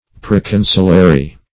Meaning of proconsulary. proconsulary synonyms, pronunciation, spelling and more from Free Dictionary.